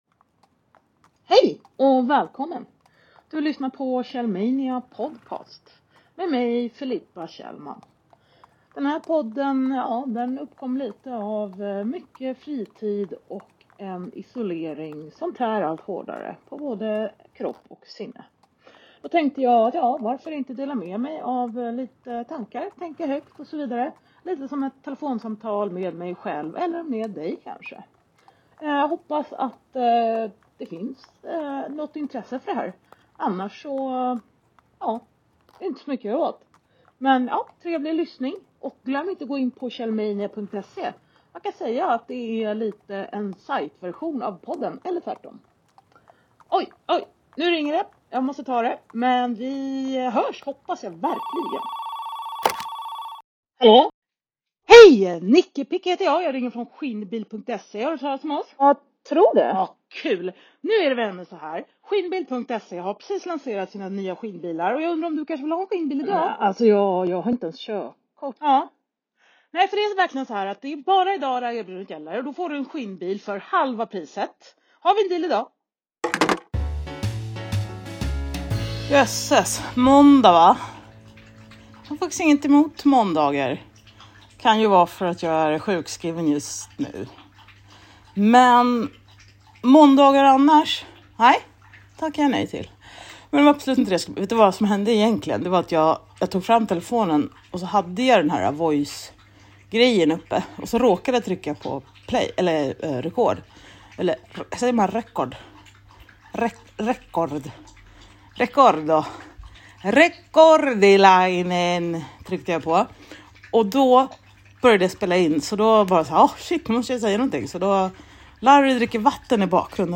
spelat in det på telefonen